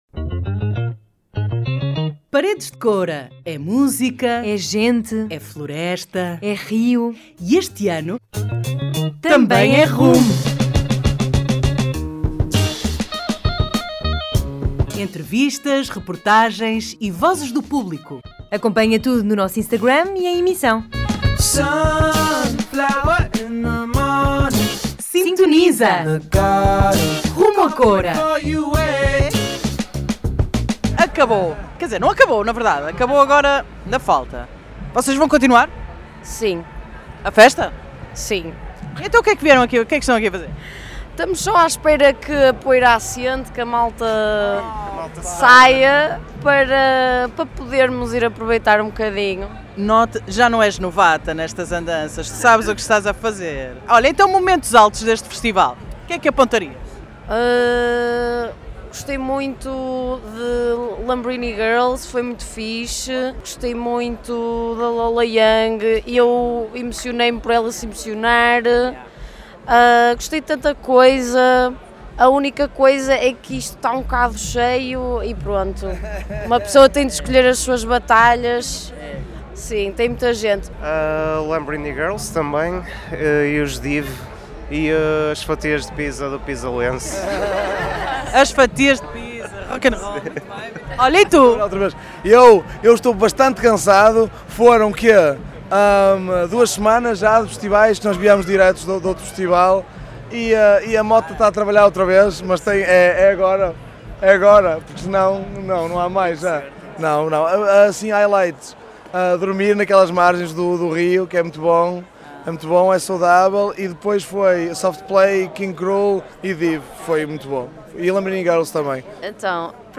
Vodafone Paredes de Coura 2025 - Vox Pop Melhores Concertos